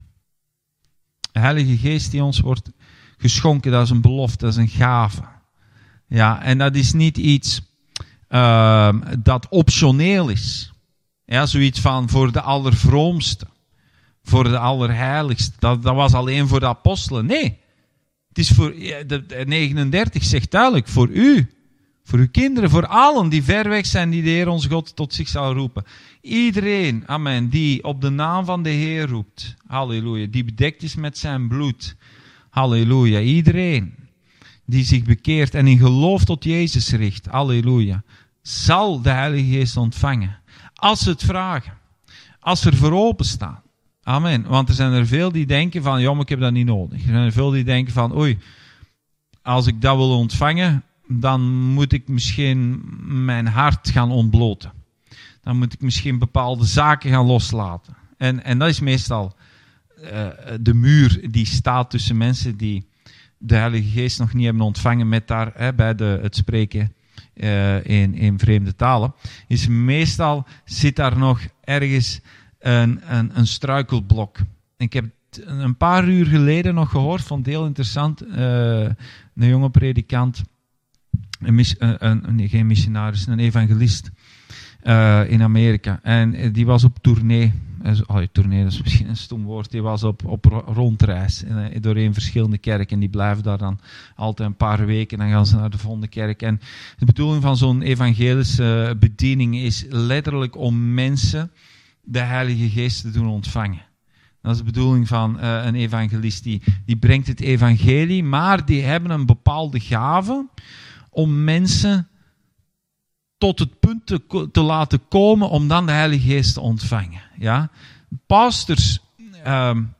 Bijbelstudie: Handelingen 2-3